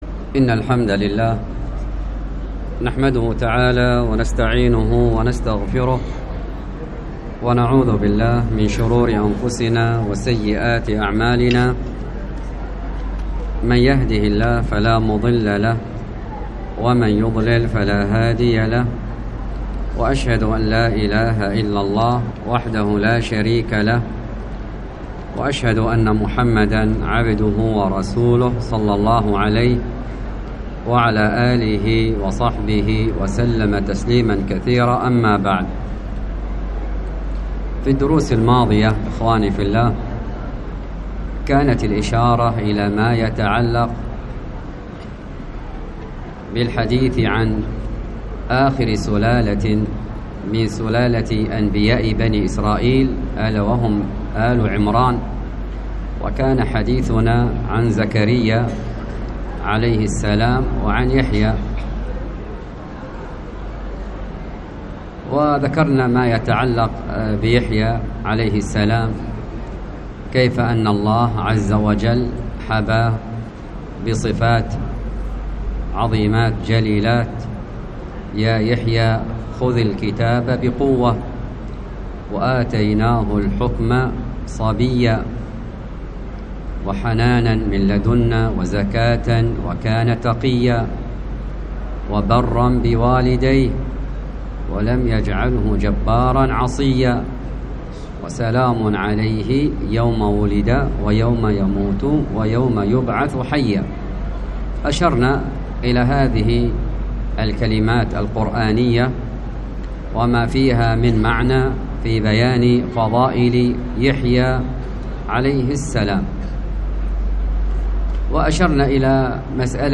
تحميل الدرس